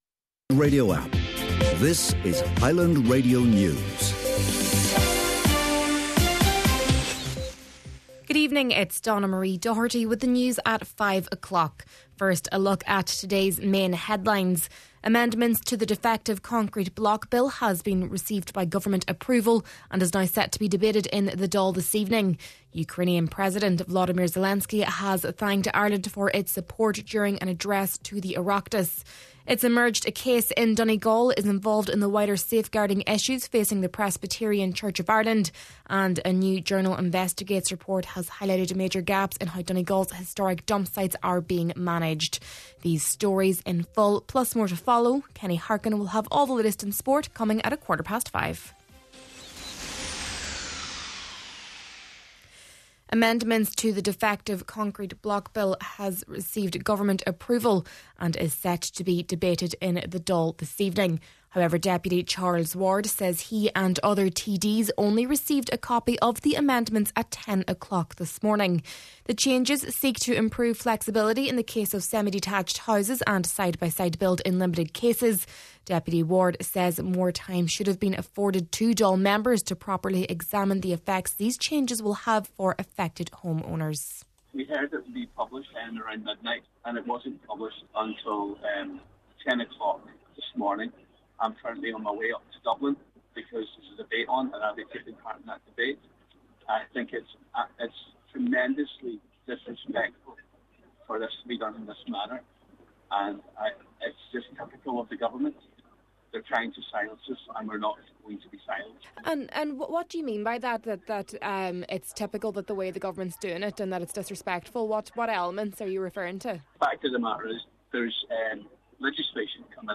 Main Evening News, Sport, and Obituary Notices – Tuesday, December 2nd